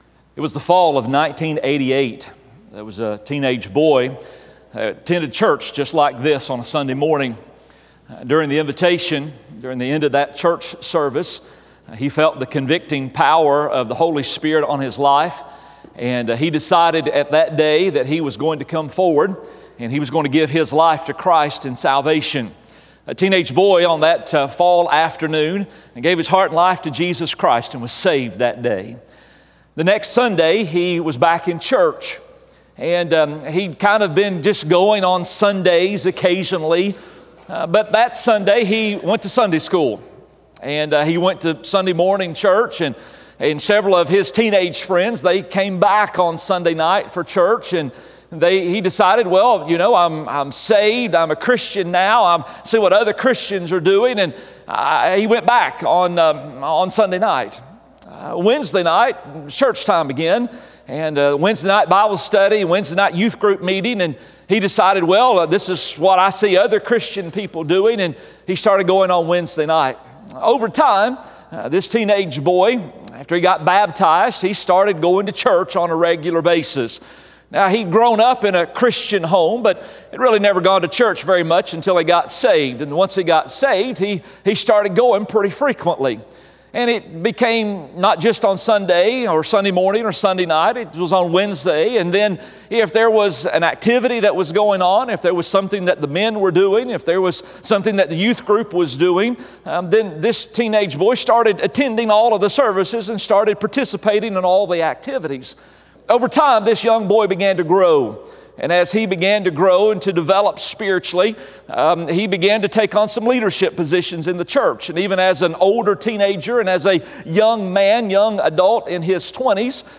Habits sermon - Gateway Church